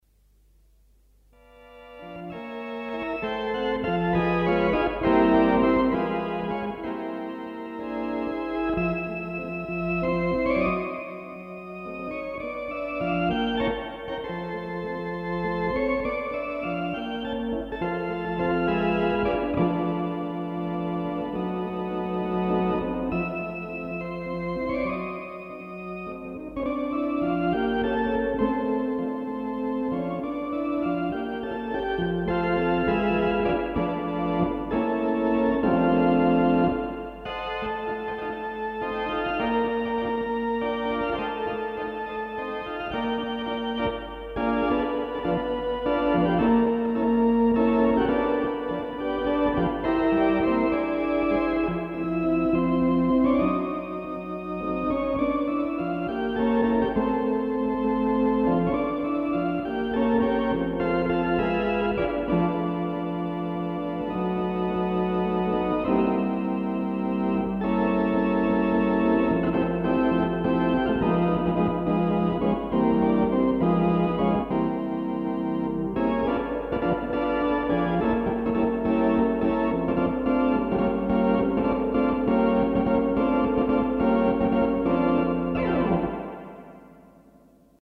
Philicorda
As the recording bug was already well installed (audio only in those days!) a few tape records were made – some of which have survived the intervening years and multiple shifts in technology and media standards.
philicorda_kh_lively1.mp3